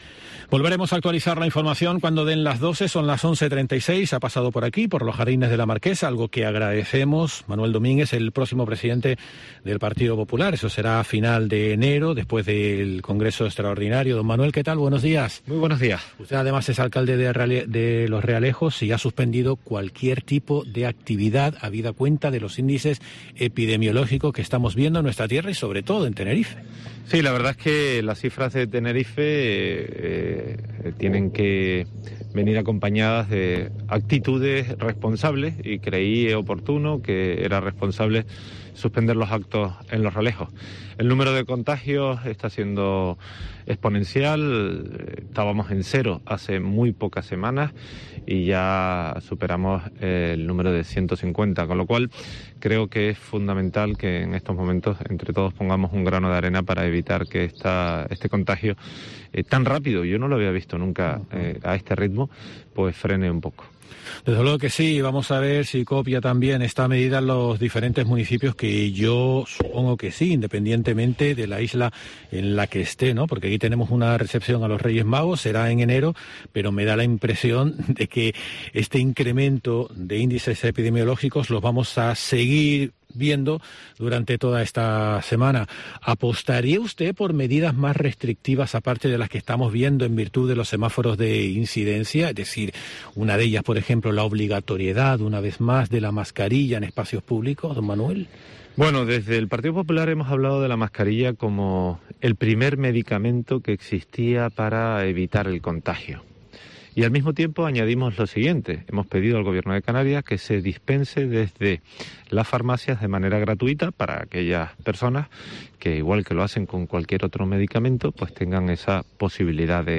Entrevista Manuel Domínguez, Candidato a la presidencia del Partido Popular